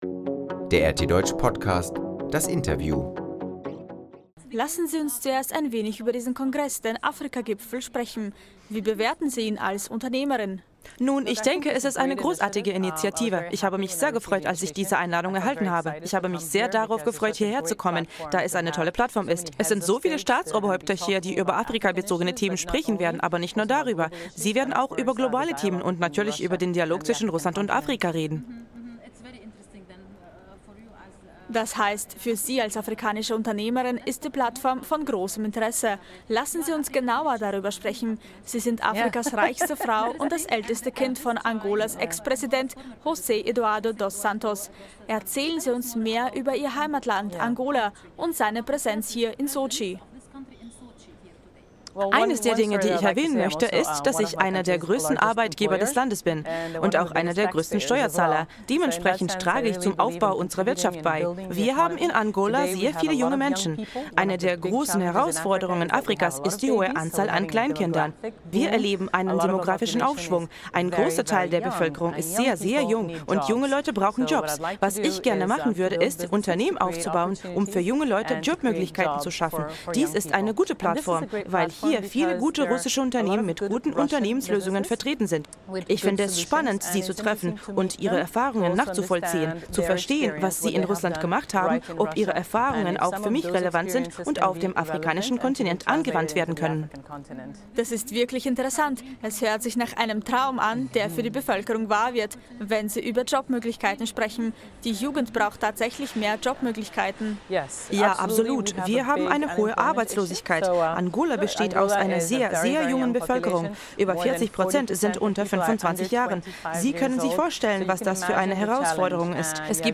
Reichste Frau Afrikas: Wir wollen den Dialog und wirtschaftliche Zusammenarbeit mit Russland Isabel Dos Santos, Afrikas reichste Frau und die Tochter des Ex-Präsidenten von Angola, nahm am Afrika-Gipfel in Sotschi teil. In einem Interview gegenüber RT lobt sie die Regierung ihres Landes für das schnelle Wirtschaftswachstum nach dem 27 Jahre langen Bürgerkrieg.